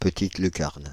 Ääntäminen
Synonyymit télévision petit écran Ääntäminen France (Île-de-France): IPA: /pə.tit ly.kaʁn/ Haettu sana löytyi näillä lähdekielillä: ranska Käännöksiä ei löytynyt valitulle kohdekielelle.